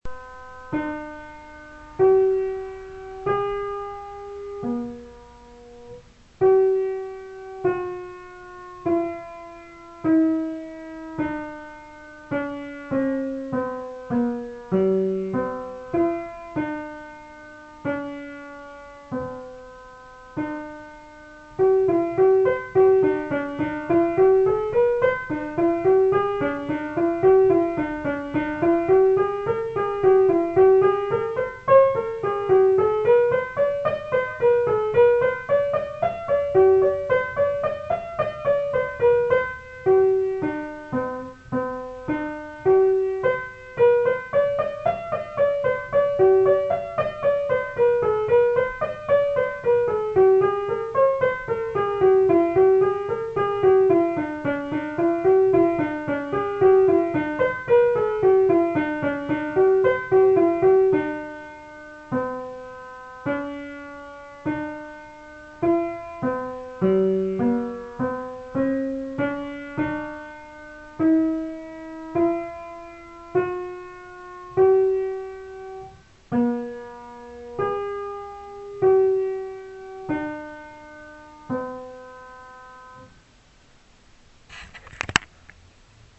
Listen here to the whole canon backwards.